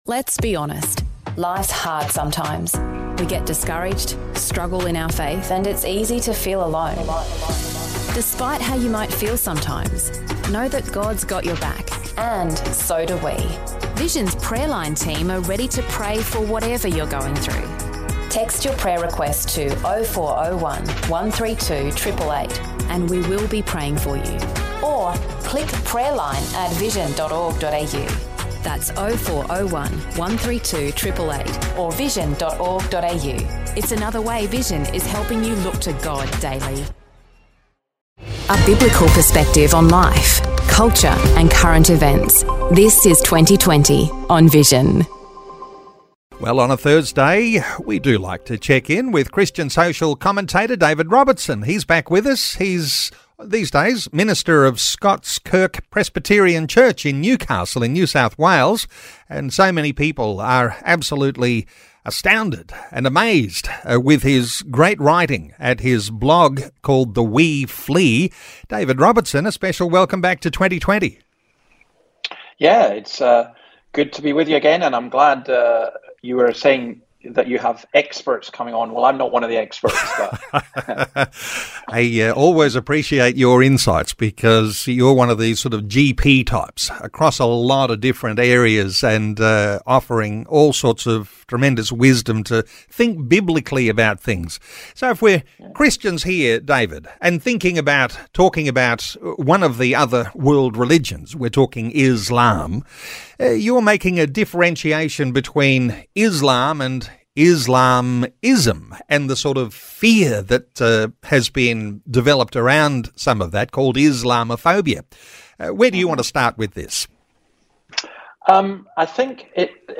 Isalmism, Islam and Christianity – A conversation